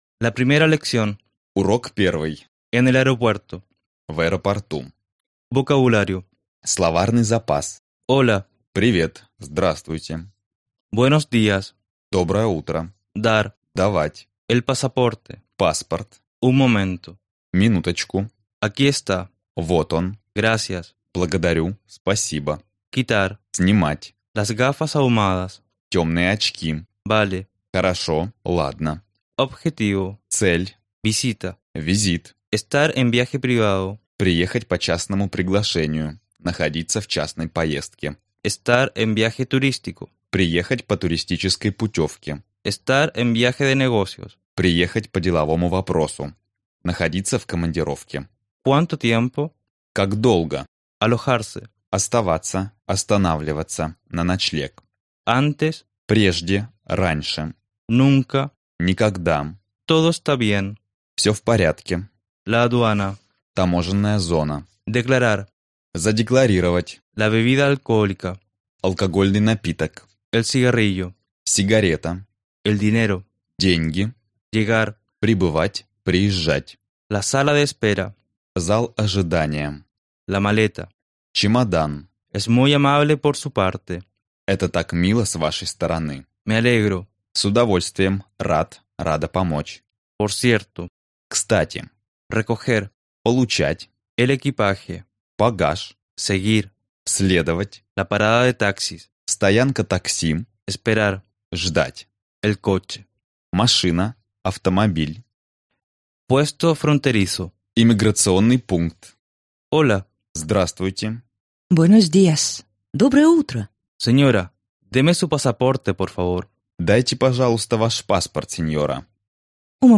Аудиокнига Испанский язык за 2 недели | Библиотека аудиокниг